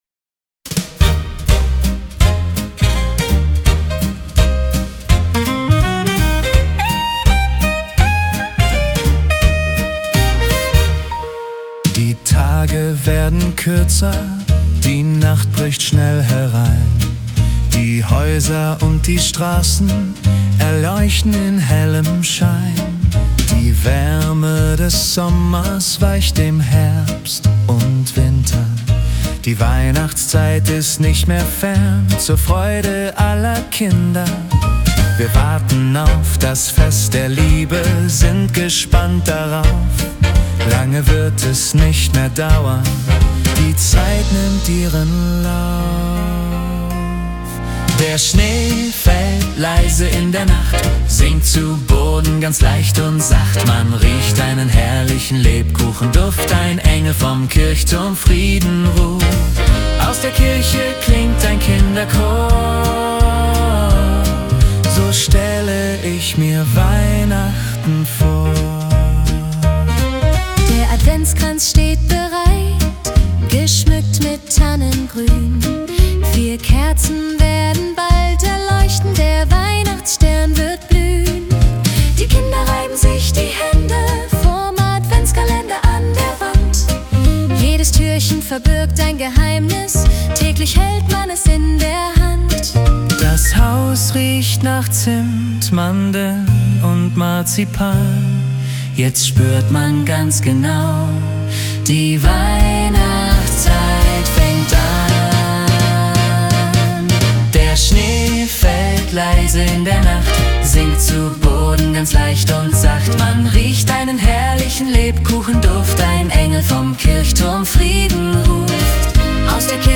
traditionelles Weihnachtslied produziert.
SWING REMIX